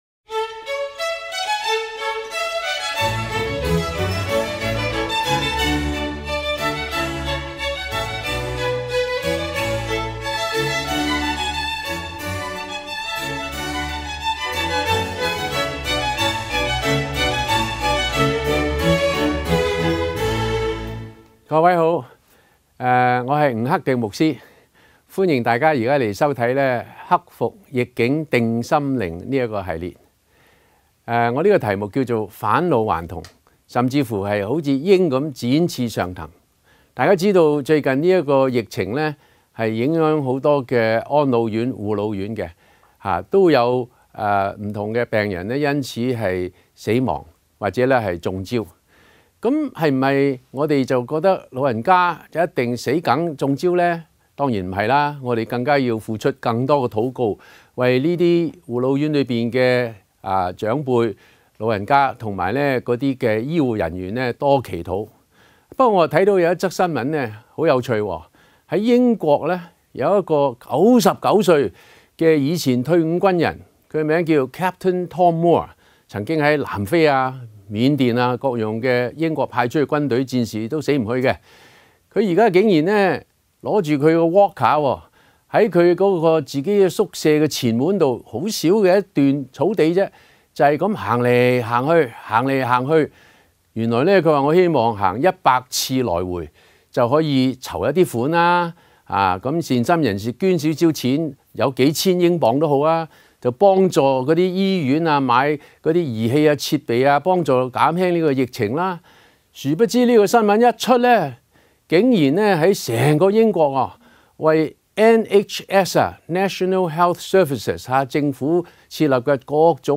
返老還童 下載粵語MP3檔案